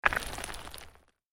دانلود آهنگ کوه 9 از افکت صوتی طبیعت و محیط
جلوه های صوتی
دانلود صدای کوه 9 از ساعد نیوز با لینک مستقیم و کیفیت بالا